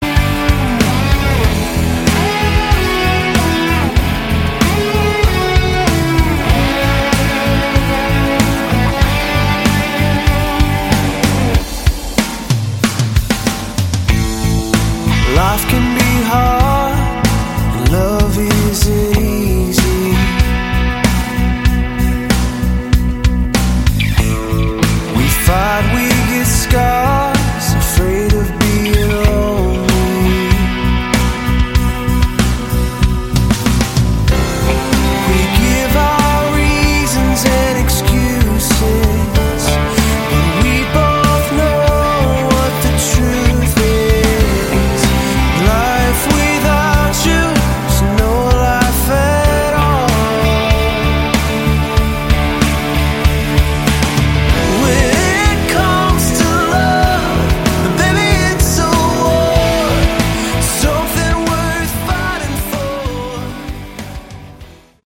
Category: Melodic Rock
Guitars, Keyboards